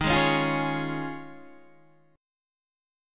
D# 9th Suspended 4th